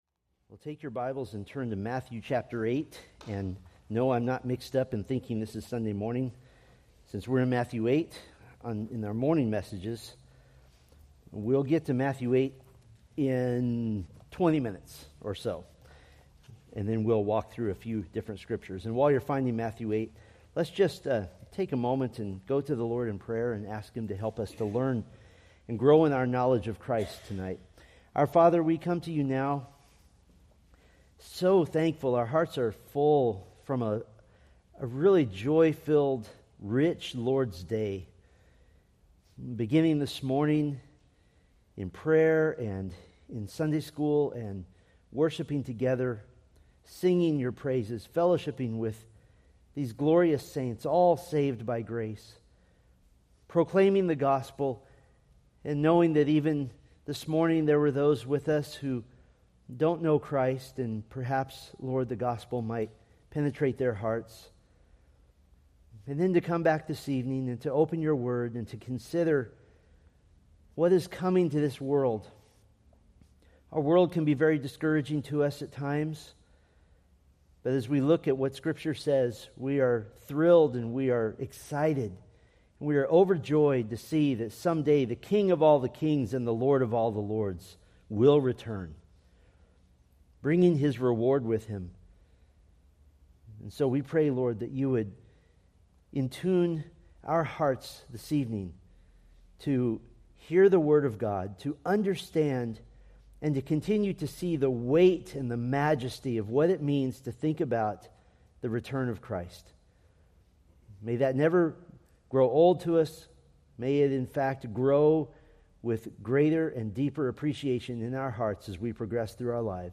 From the Millennium: New Testament Witnesses sermon series.
Sermon Details